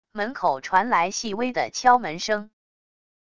门口传来细微的敲门声wav音频